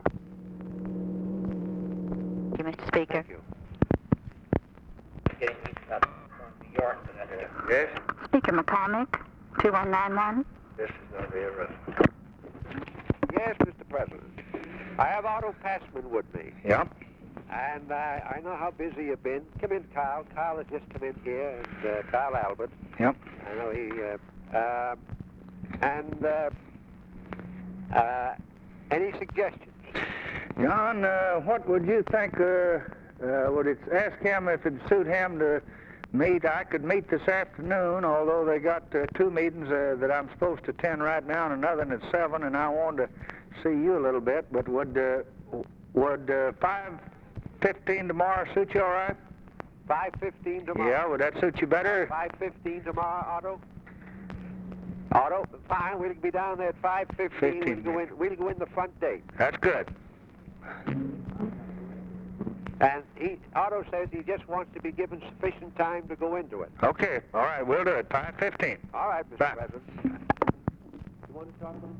Conversation with JOHN MCCORMACK, December 10, 1963
Secret White House Tapes